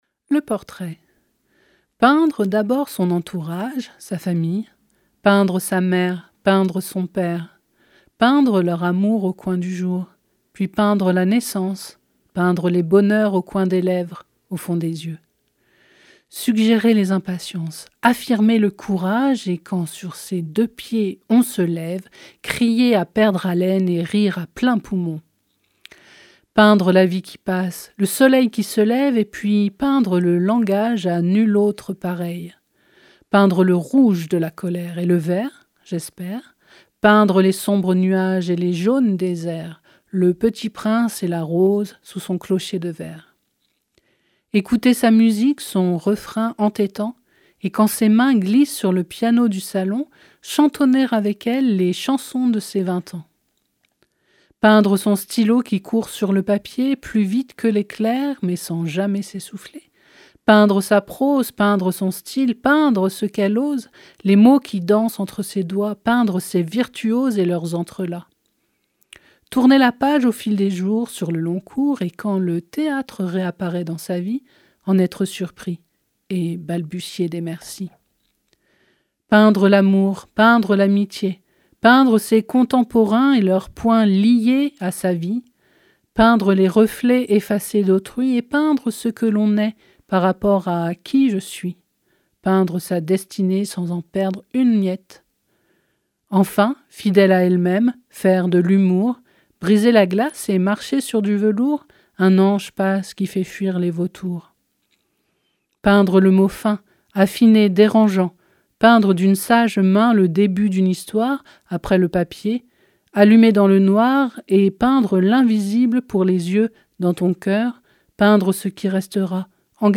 🎧 Le portrait - Les ateliers de fictions radiophoniques de Radio Primitive